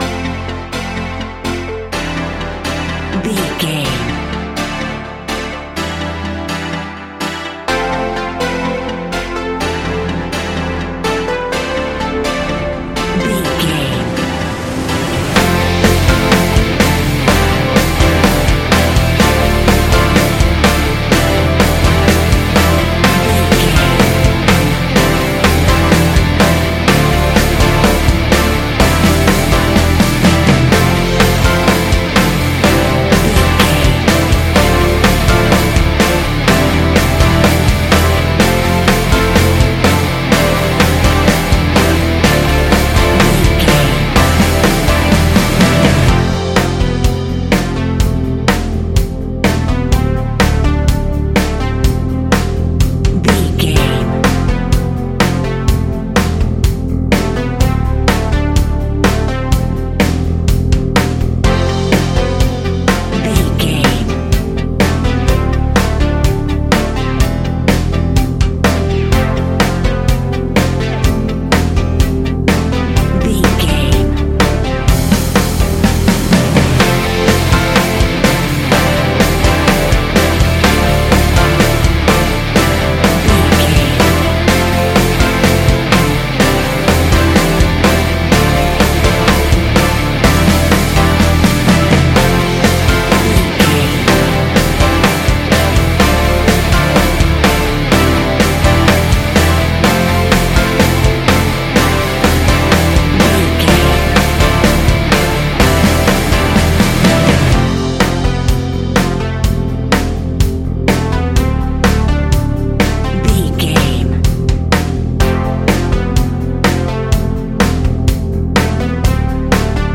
Ionian/Major
D
ambient
downtempo
synth
pads